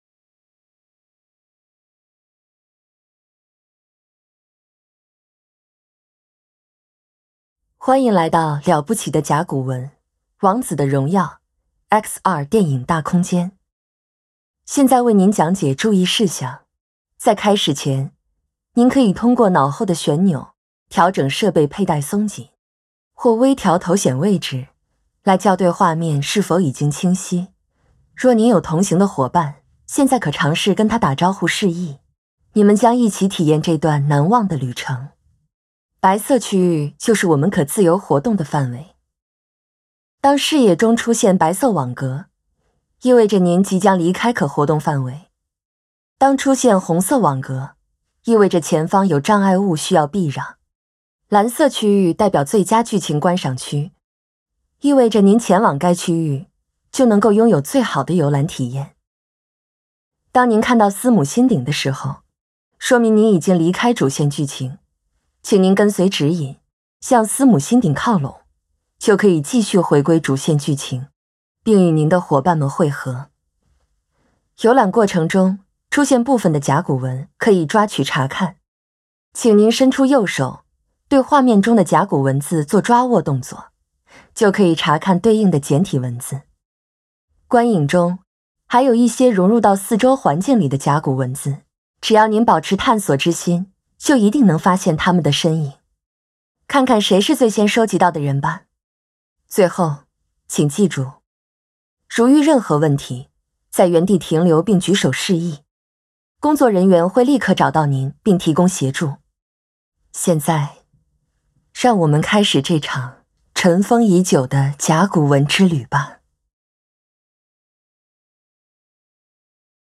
ADR_Intro_VoiceGuide_01.mp3